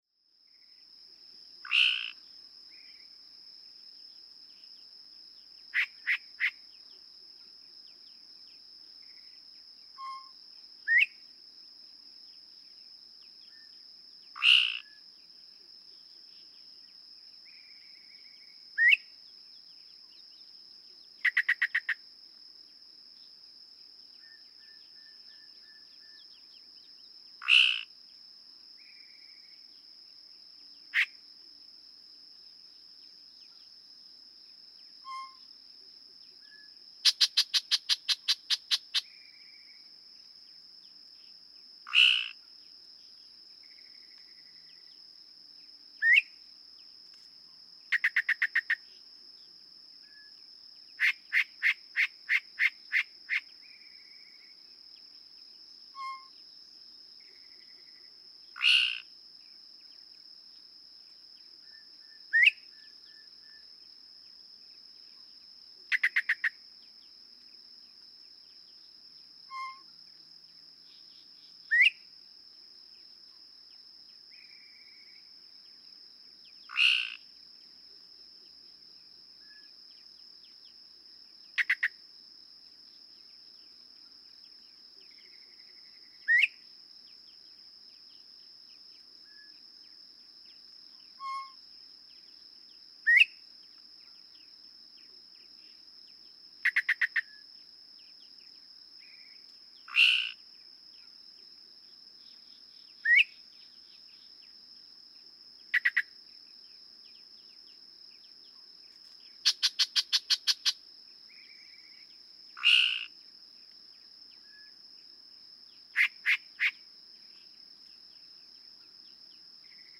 Yellow-breasted chat
Listen for the BOB and WHITE notes, almost certainly mimicked from the northern bobwhite, and mentally mark every time you hear those two notes. At some point, you no longer hear those notes because the chat has moved on to a new "package" of song phrases.
Shawnee National Forest, Illinois.
433_Yellow-breasted_Chat.mp3